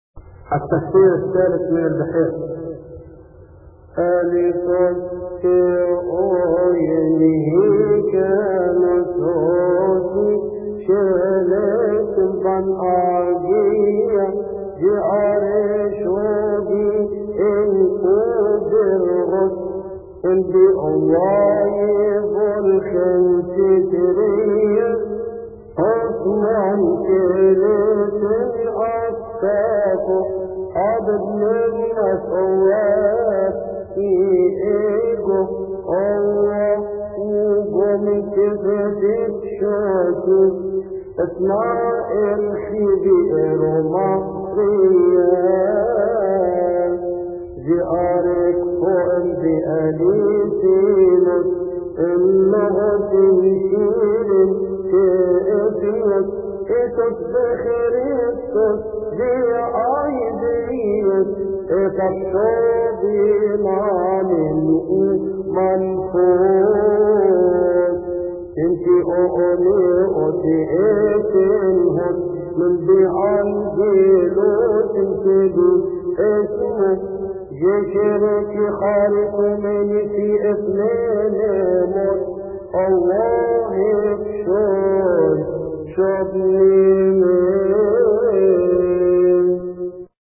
يصلي في تسبحة عشية أحاد شهر كيهك